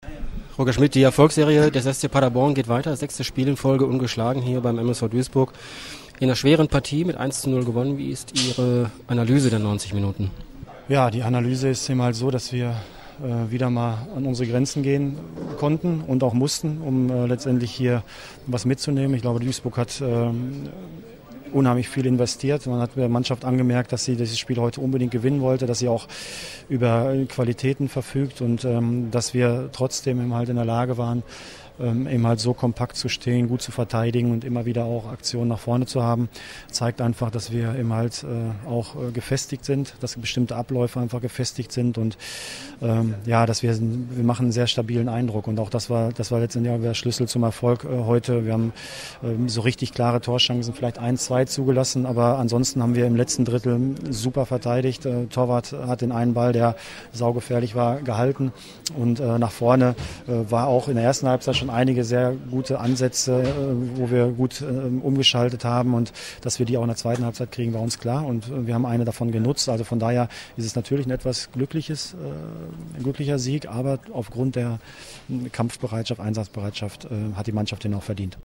Audiokommentar
Chef-Trainer Roger Schmidt zum Spiel